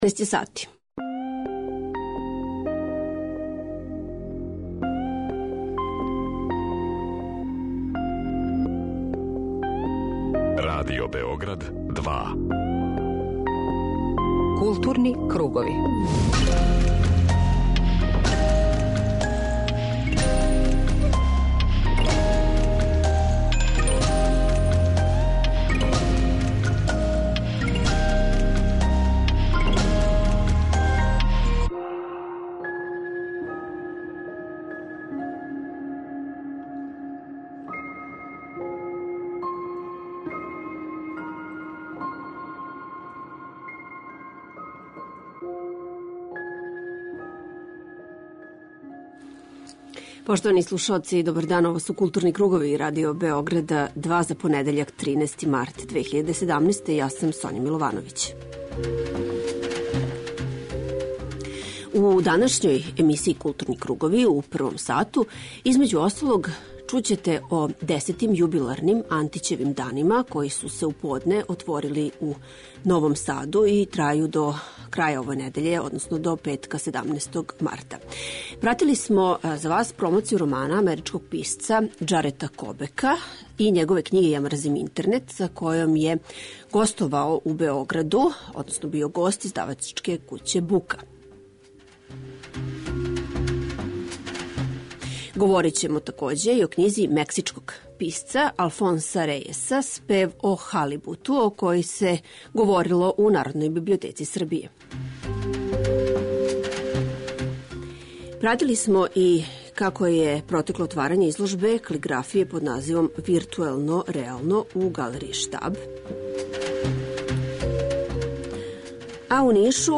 У тематском блоку Арс сонора моћи ћете да чујете како су протекли осмомартовски концерти Симфонијског оркестра РТС-а и ансамбла Метморфозис, како је свирала Београдска филхармонија на концерту посвећеном успомени на Зорана Ђинђића, како је музицирао Васил Хаџиманов и како се београдској публици представила група Istanbul Night.